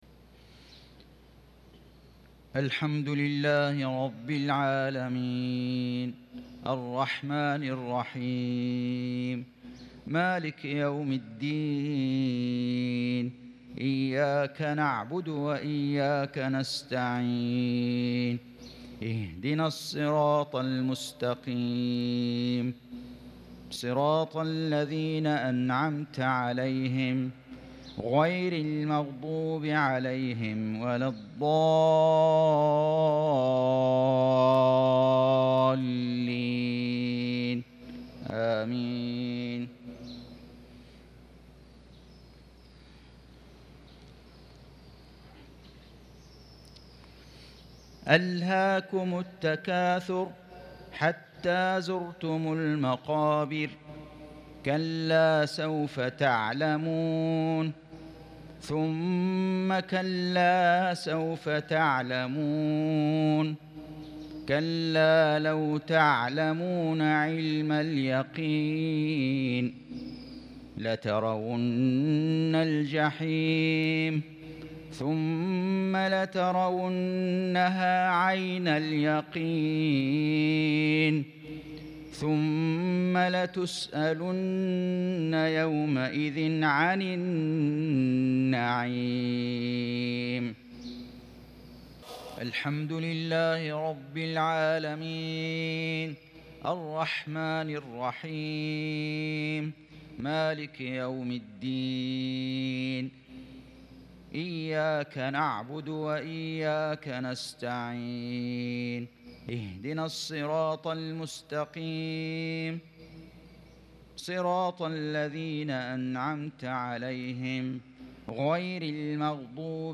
مغرب الاثنين 5-3-1443هـ سورتي التكاثر والهمزة | Maghrib prayer from Surah At-Takathur & Al-Humazah 11/10/2021 > 1443 🕋 > الفروض - تلاوات الحرمين